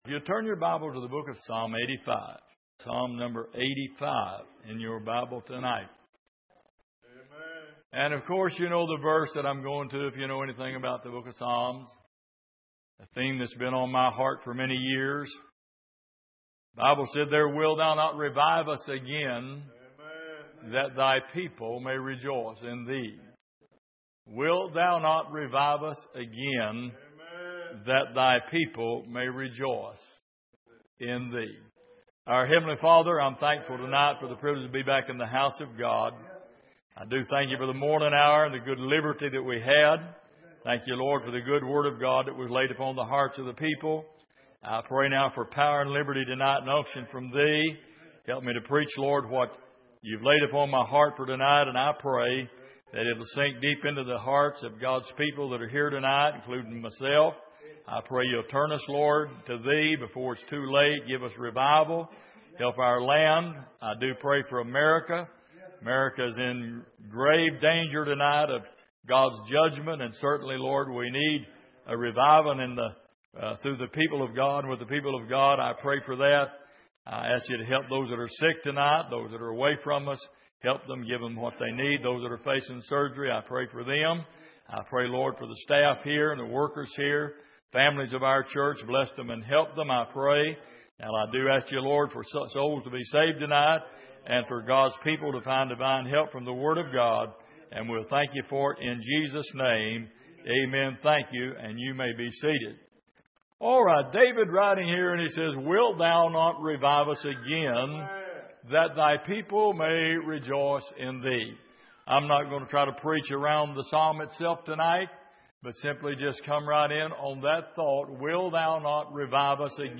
Exposition of the Psalms Passage: Psalm 85:6 Service: Sunday Evening Wilt Thou Not Revive Us Again?